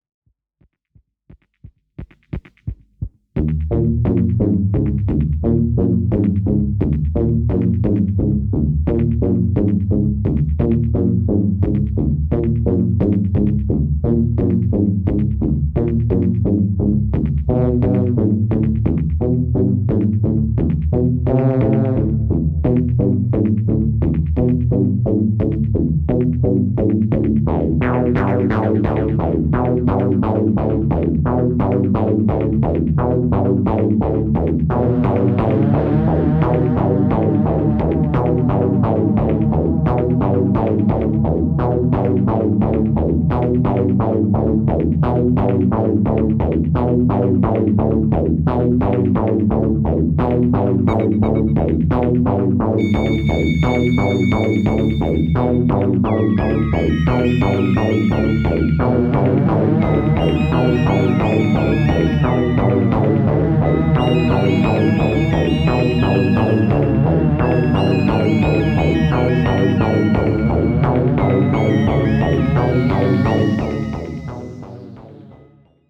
Electro Electronix